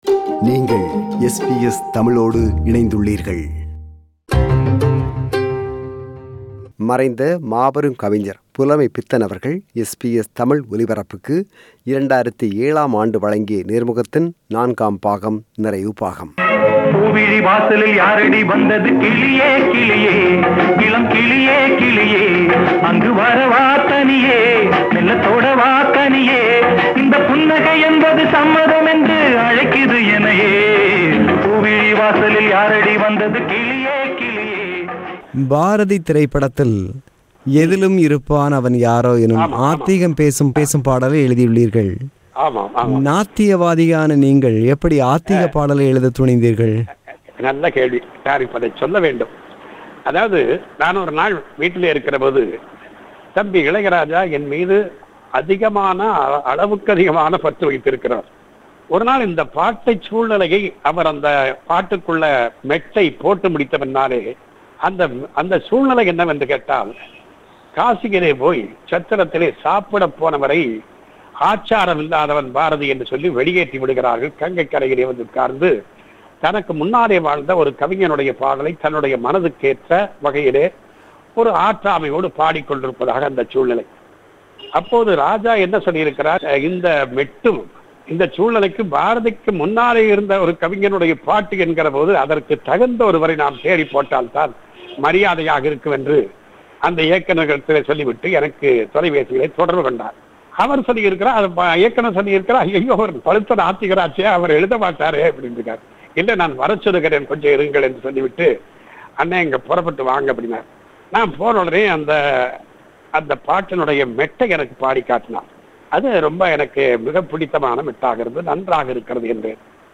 Interview with Poet Pulamaipithan – Part 4